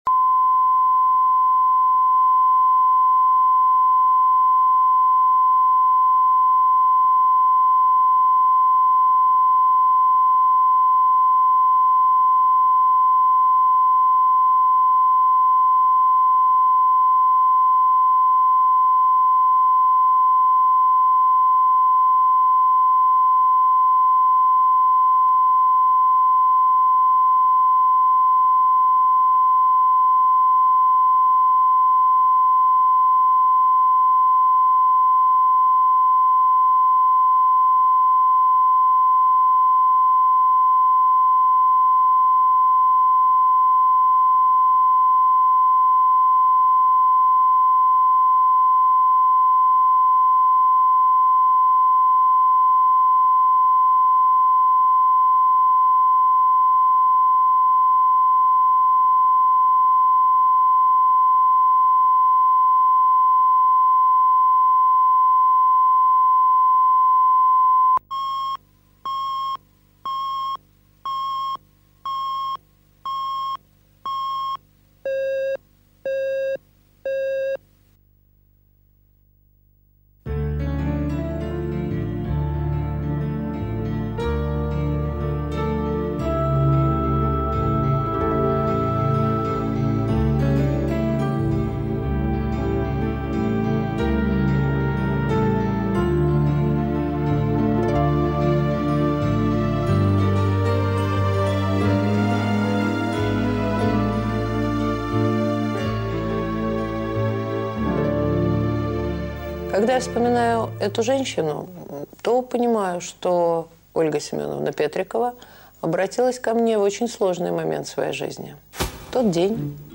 Аудиокнига Мужчина в доме | Библиотека аудиокниг